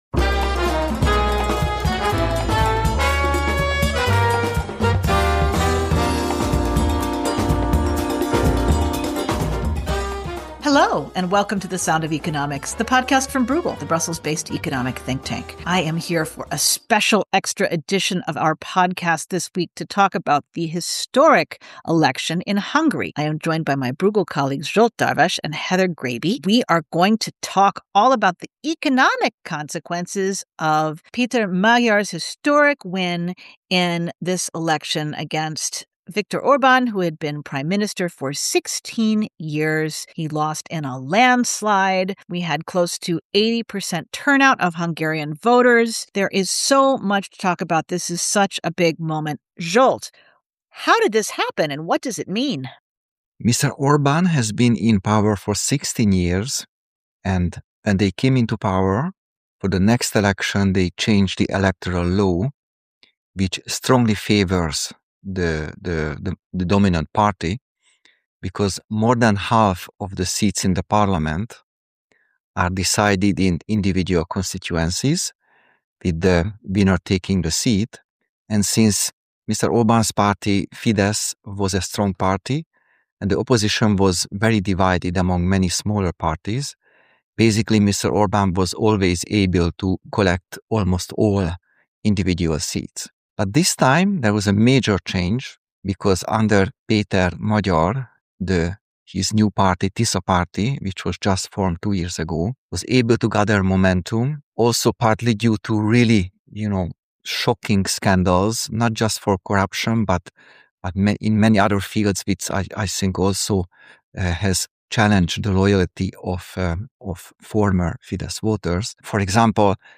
The discussion covers all this and more after nearly 80% of Hungarian voters went to the polls.